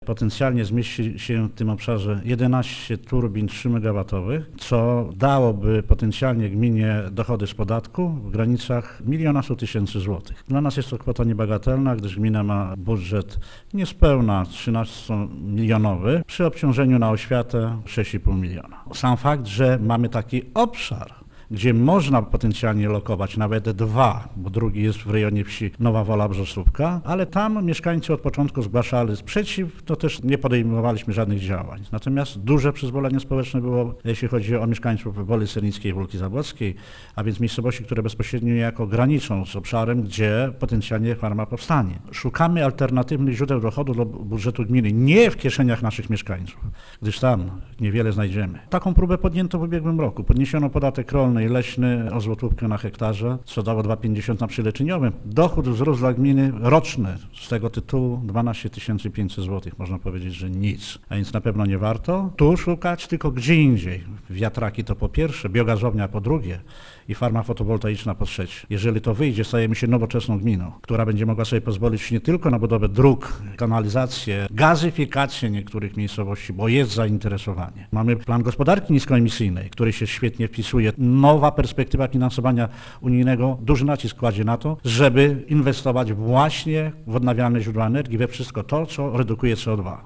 Mimo to Stanisław Marzęda zapewnia, że on sam i grupa zwolenników budowy wiatraków nie rezygnują z pomysłu postawienia turbin w obszarze wsi Wola Sernicka: